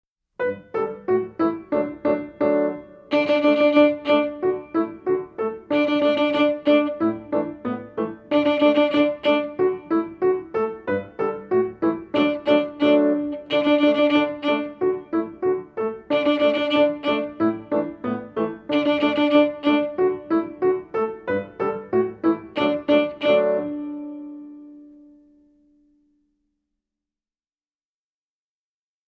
Voicing: Viola w/ Audio